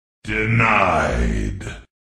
Denied - Gaming Sound Effect (HD)
Category: Sound FX   Right: Personal